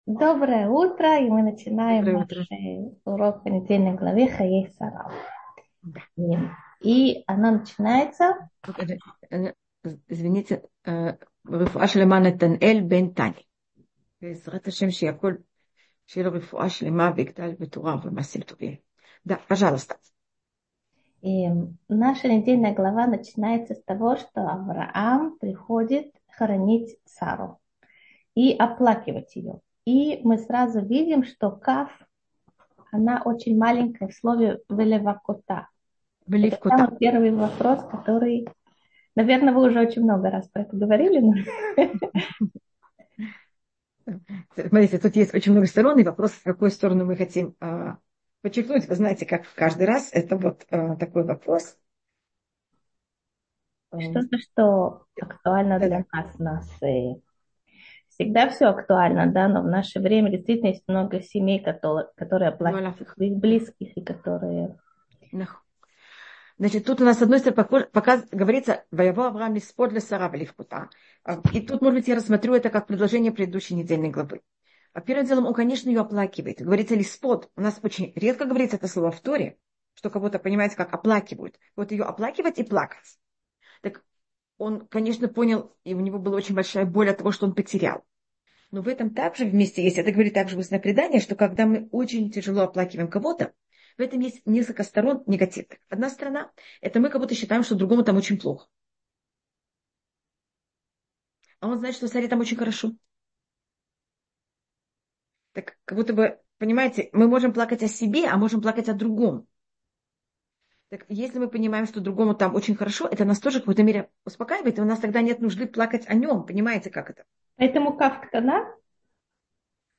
Утренний зум в гостях у Толдот. Почему после хороших поступков часто так плохо?